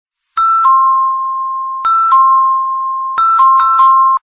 门铃音效
DINGDONG_DEFAULT.mp3